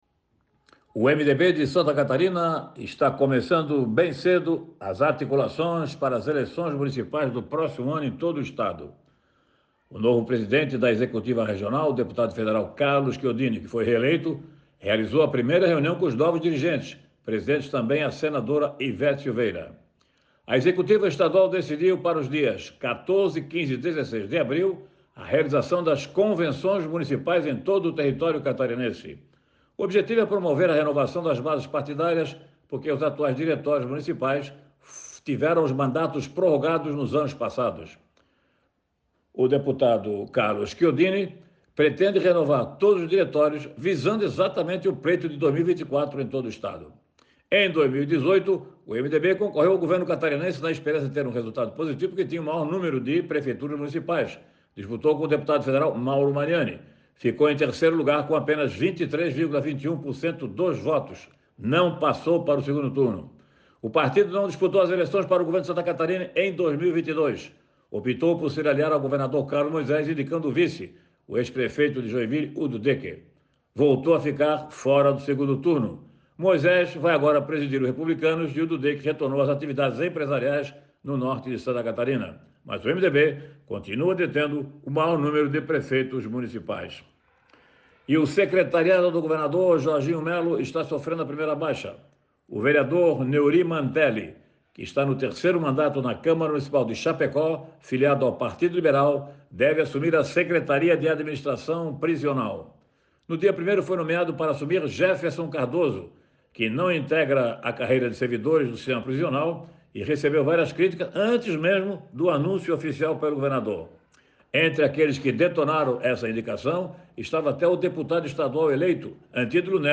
O jornalista comenta sobre as articulações antecipadas do MDB (Movimento Democrático Brasileiro) em SC visando as eleições municipais no próximo ano
Confira o comentário na íntegra